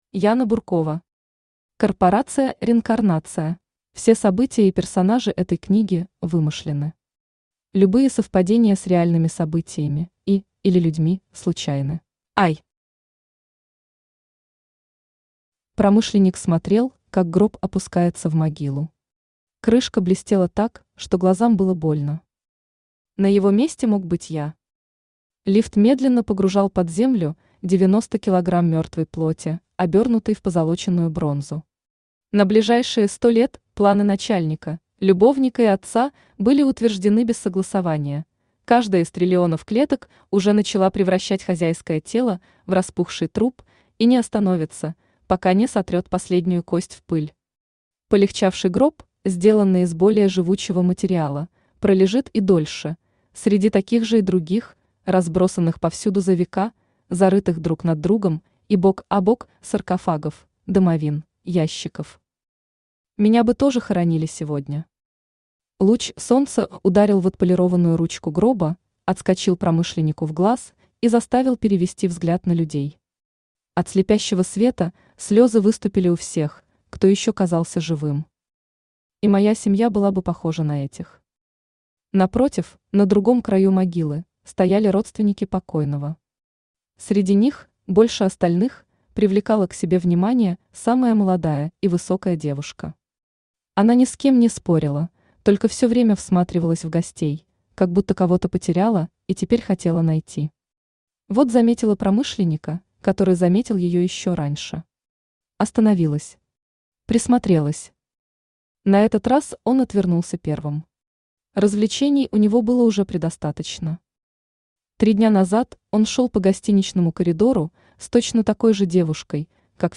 Аудиокнига Корпорация «Реинкарнация» | Библиотека аудиокниг